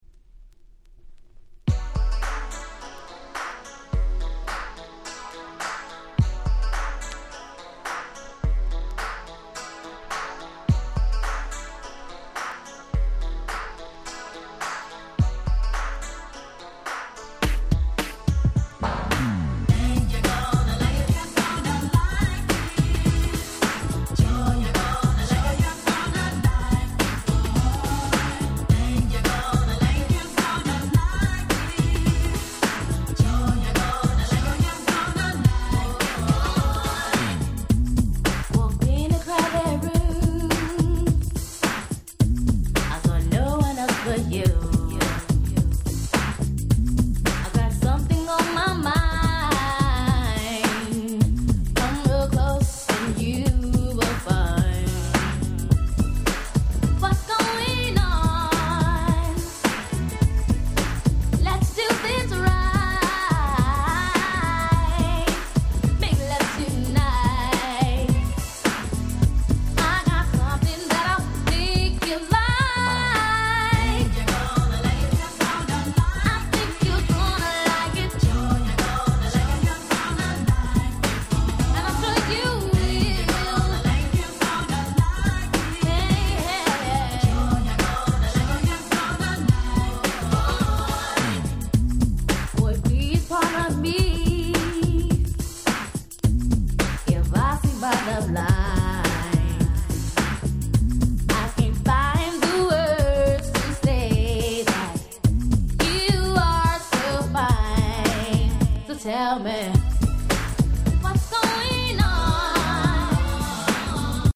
【Media】Vinyl LP
【Condition】B- (薄いスリキズ多め。プチノイズ箇所あり。試聴ファイルでご確認願います。)
92' Big Hit R&B LP !!